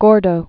(gôrdō)